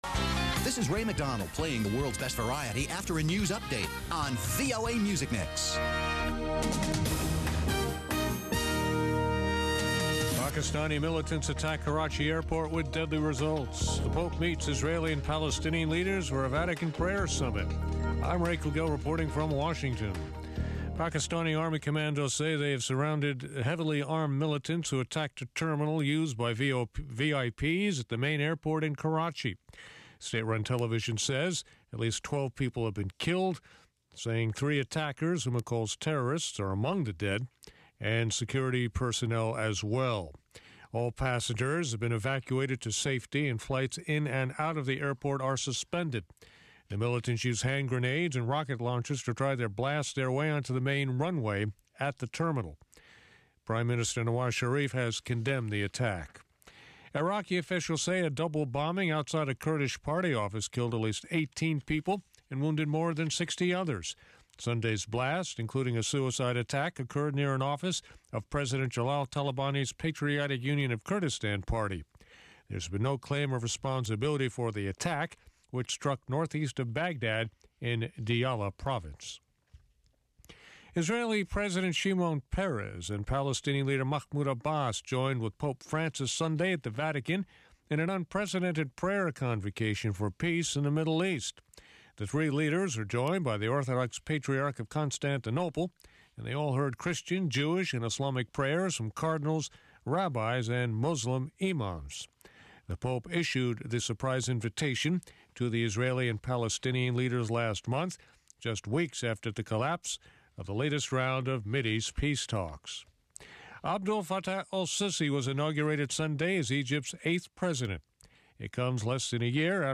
De lunes a viernes, a las 8:00pm [hora de Washington], un equipo de periodistas y corresponsales analizan las noticias más relevantes.